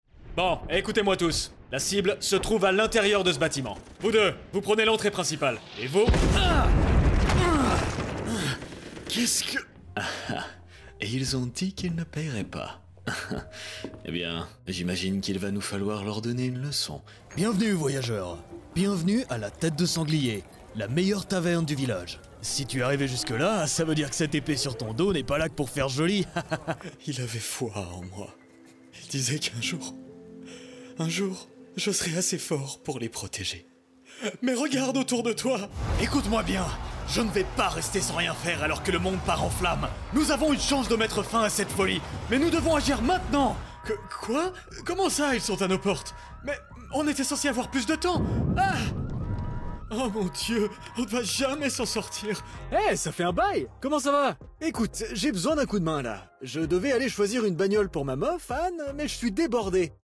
中低音域の声で、暖かさと親しみやすさを兼ね備えています。
いろいろなキャラクター(フランス語)